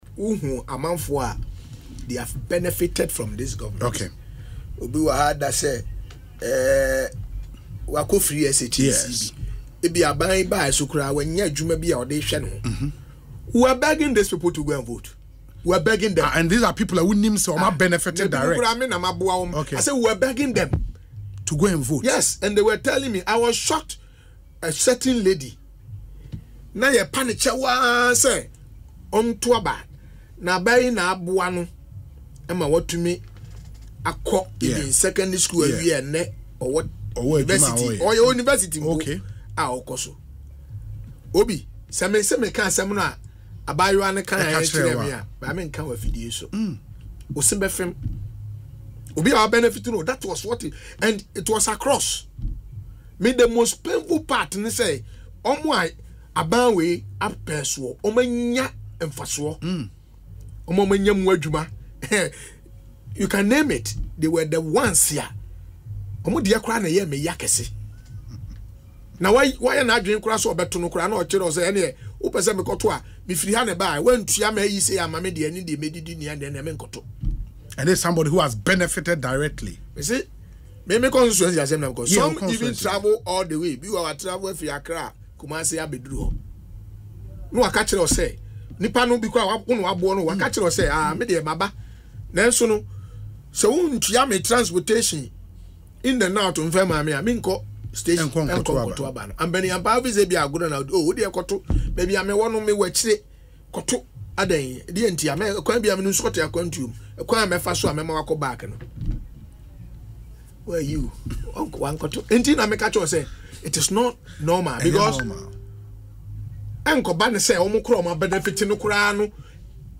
Mr Nyarko expressed these concerns in an interview on Asempa FM’s Ekosii Sen.